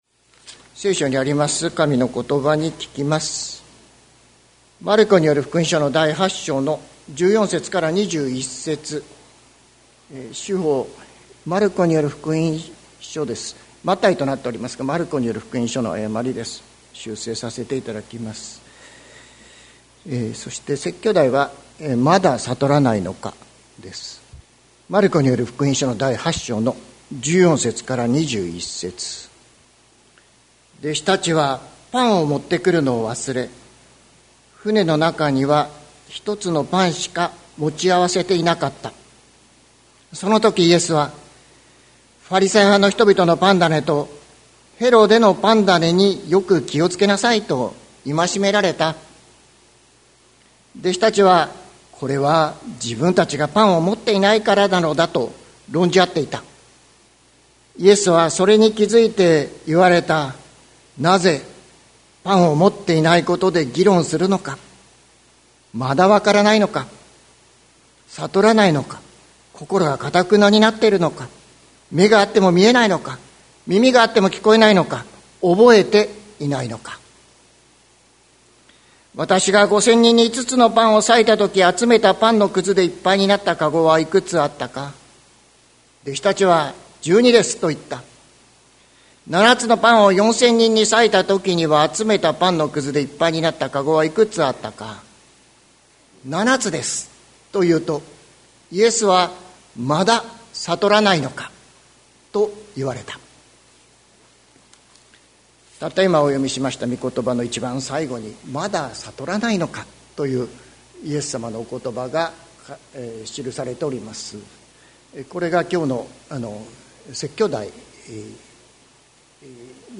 2026年02月15日朝の礼拝「まだ悟らないのか」関キリスト教会
説教アーカイブ。